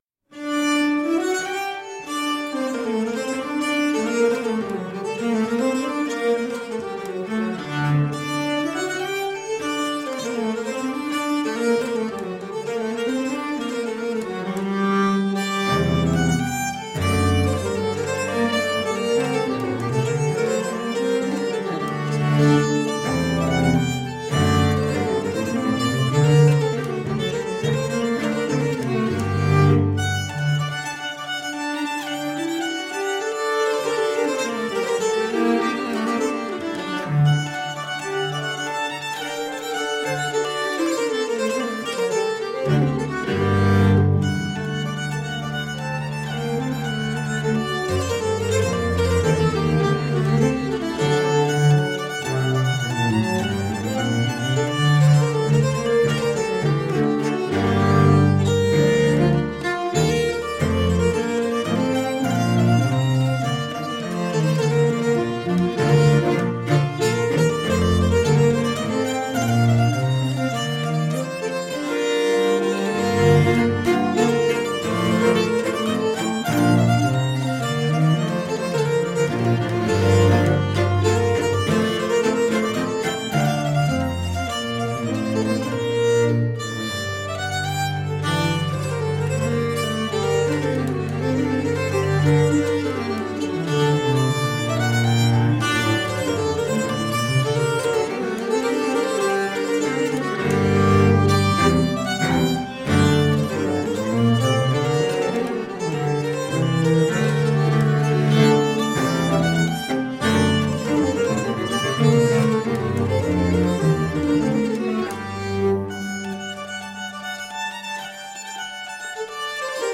Scandinavian folkish cello.
Tagged as: World, New Age, Cello, Holiday